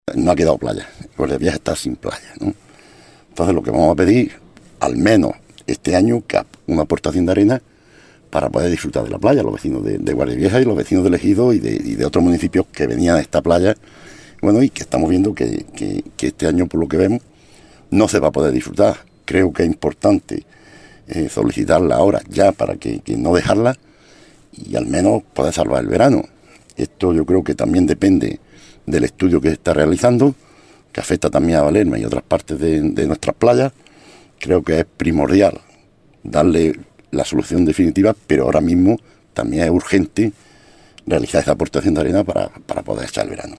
José Miguel Alarcón. Portavoz del PSOE en el Ayuntamiento de El Ejido